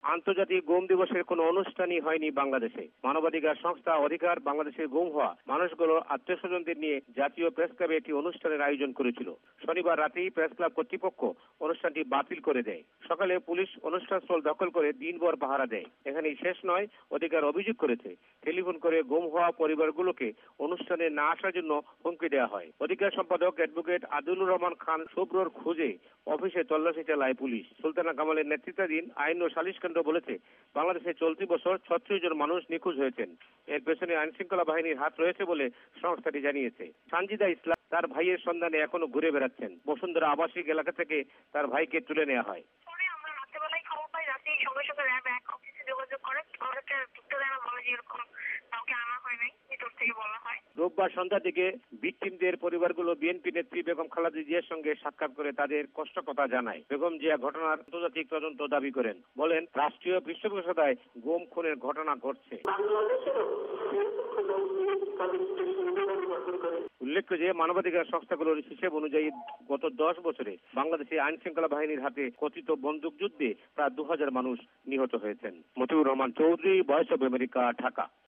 VOA রিপোর্ট